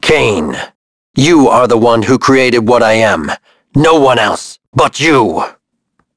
Shakmeh-vox-dia_02.wav